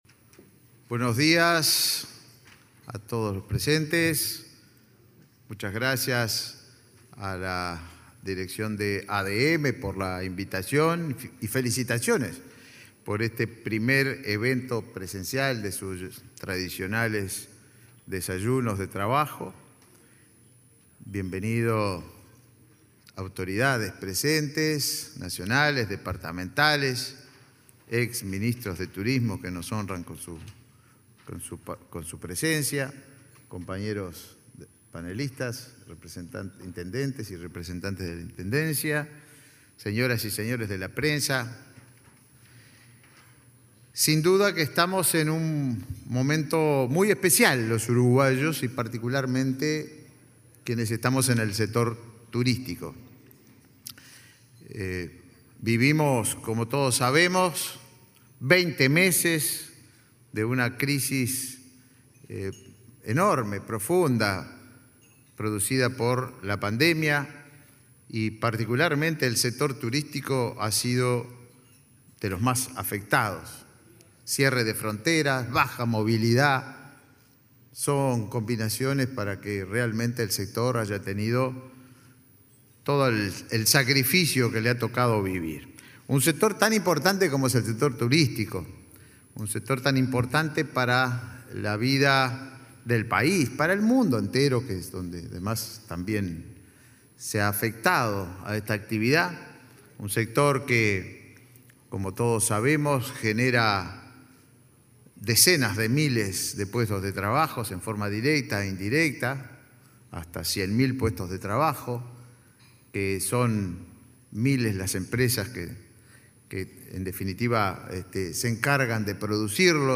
Disertación sobre perspectivas del turismo en la próxima temporada, organizado por ADM